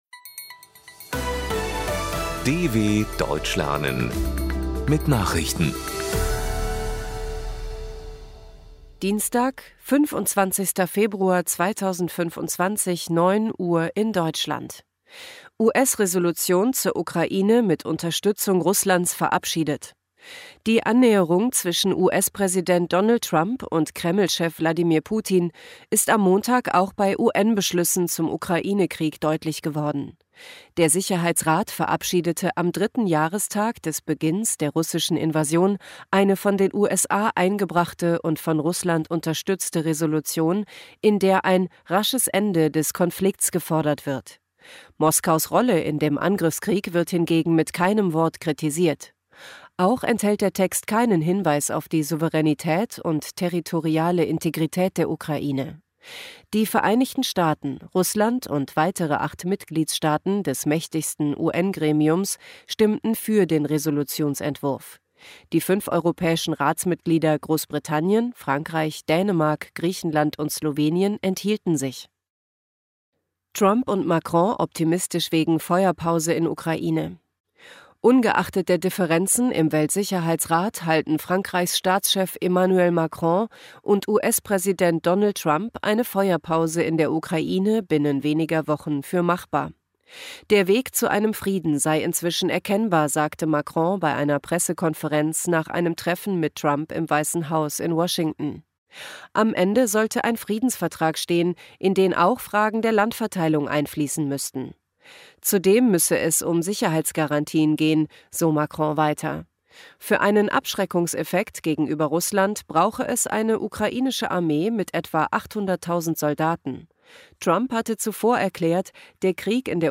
25.02.2025 – Langsam Gesprochene Nachrichten
Trainiere dein Hörverstehen mit den Nachrichten der DW von Dienstag – als Text und als verständlich gesprochene Audio-Datei